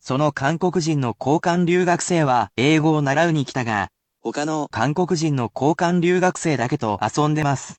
Though I will read these sentences personally at a normal speed for more advanced learners, it will still help you learn how to use it.